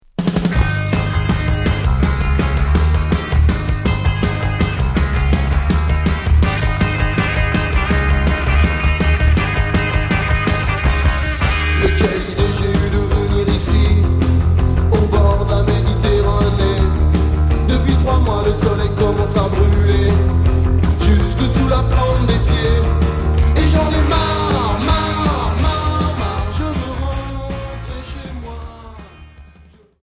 Rock Mod Unique 45t retour à l'accueil